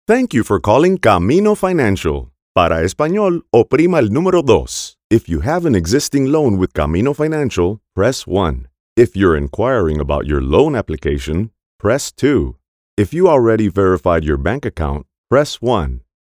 Male
Adult (30-50)
Phone Greetings / On Hold
Bilingual Phone Hold Ivr
0907Hold_PhoneIVR_Bilingual.mp3